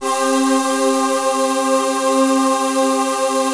VOICEPAD04-LR.wav